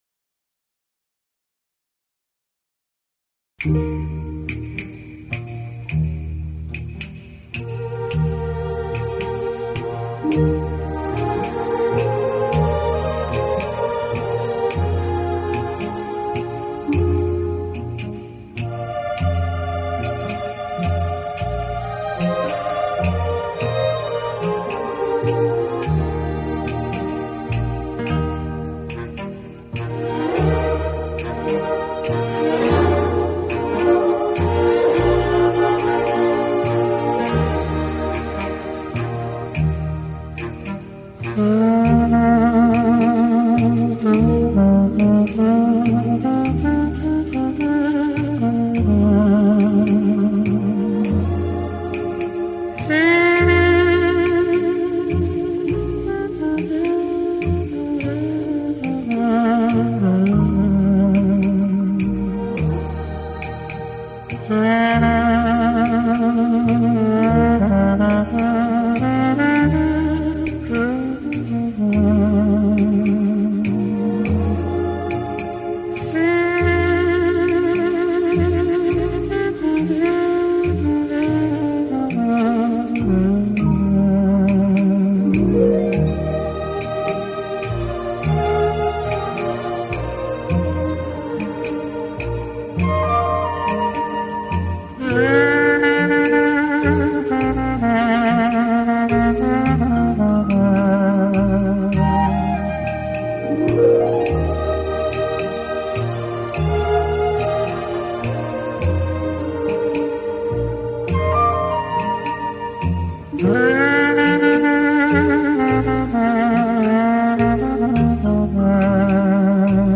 老唱片，经典浪漫轻柔的拉丁风格，正是好歌不厌百回听；在以吹奏的乐器中，感情的